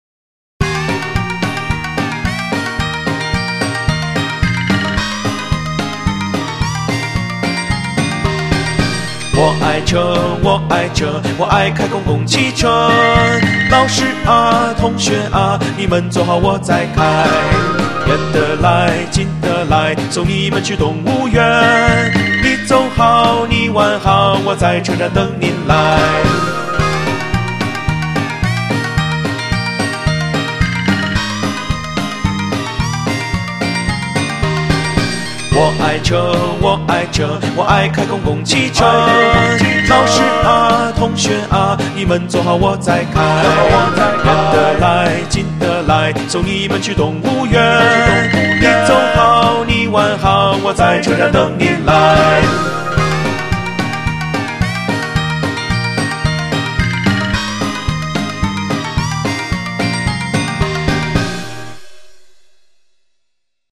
Cùng hát nhé